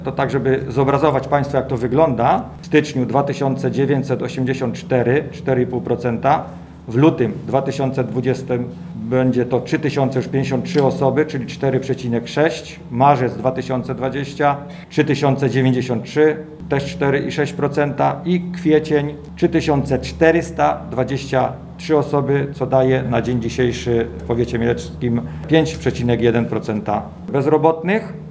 Mówi Maciej Jemioło członek zarządu Powiatu Mieleckiego.